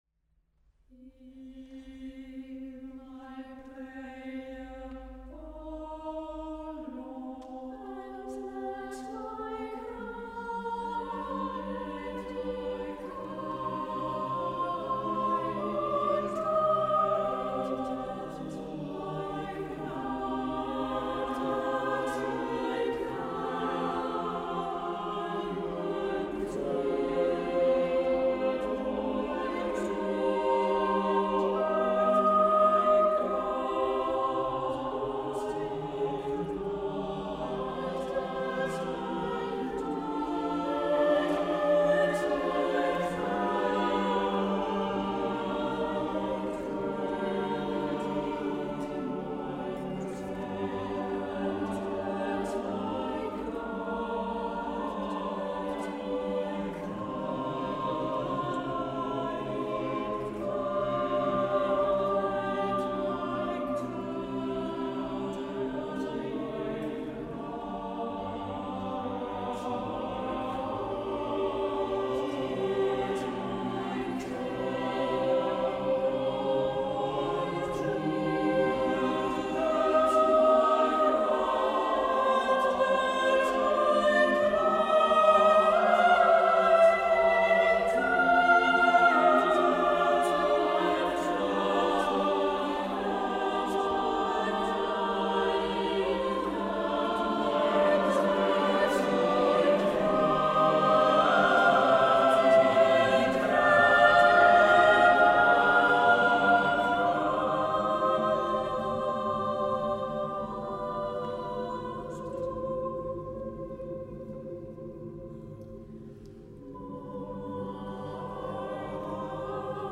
Live-Mitschnitte Konzerte 2023